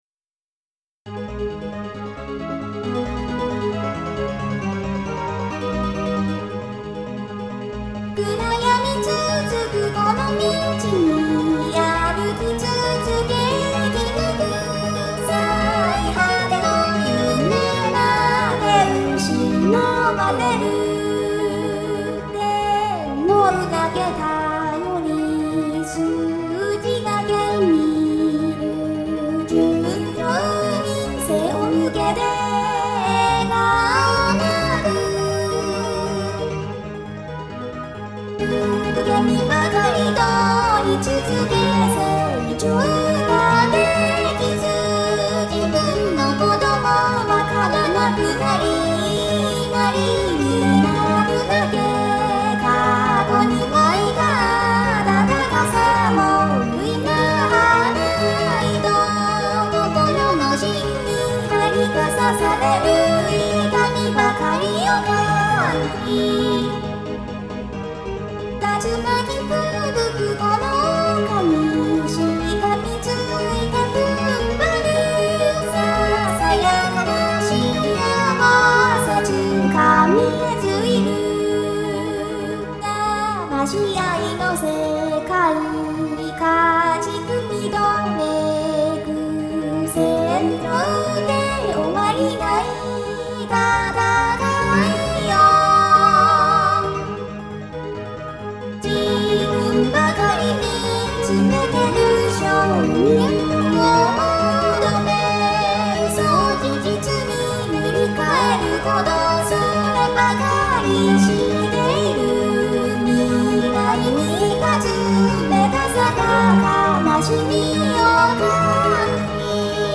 〜ボーカル版〜